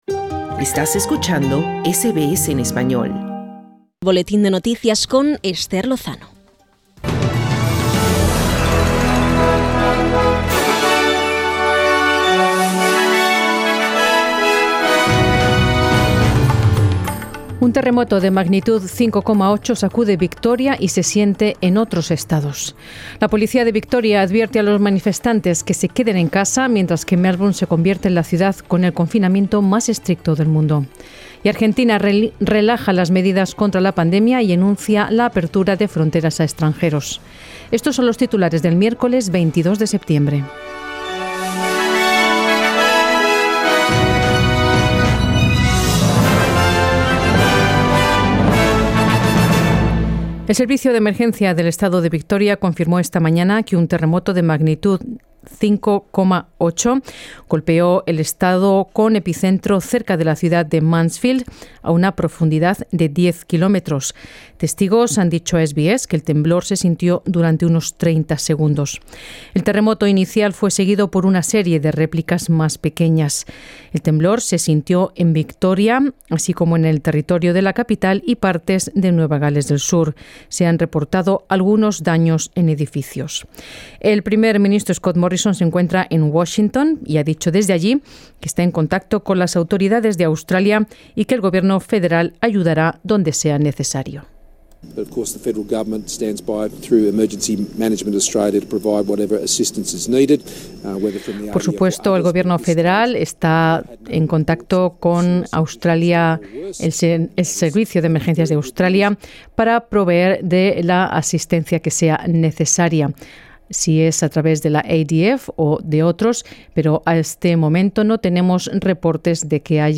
Noticias SBS Spanish | 22 septiembre 2021